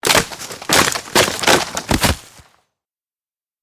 Tree Fall 5
Tree Fall 5 is a free sfx sound effect available for download in MP3 format.
yt_8yUyuBnoO_Q_tree_fall_5.mp3